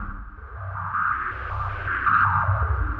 Index of /musicradar/rhythmic-inspiration-samples/80bpm